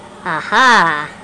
Ah Ha (cartoon) Sound Effect
Download a high-quality ah ha (cartoon) sound effect.
ah-ha-cartoon-1.mp3